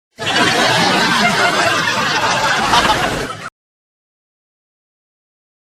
Laughs 3